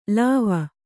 ♪ lāva